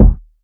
Kicks
KICK.141.NEPT.wav